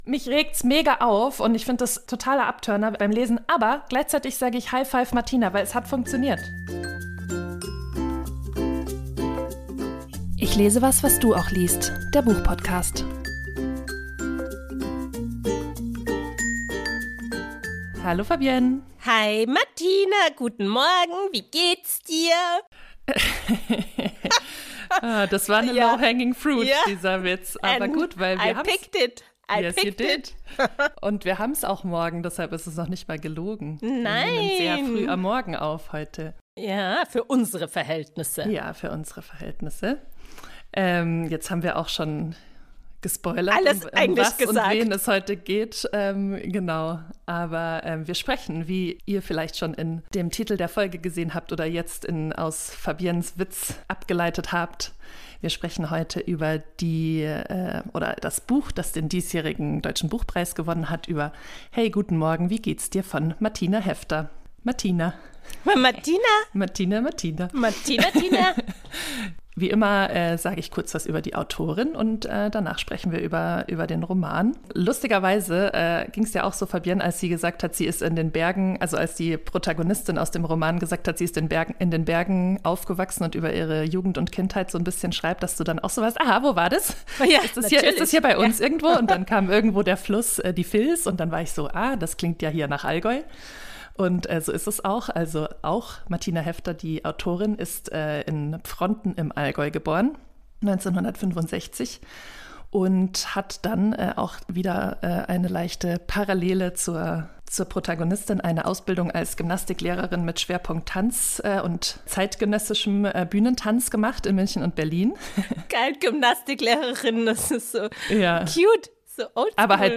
Diskussionen Podcasts